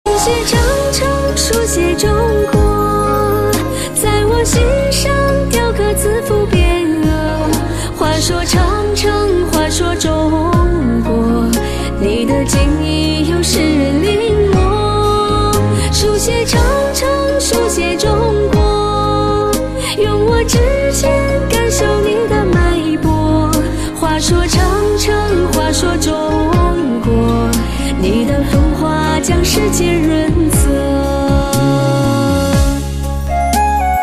M4R铃声, MP3铃声, 华语歌曲 77 首发日期：2018-05-15 22:45 星期二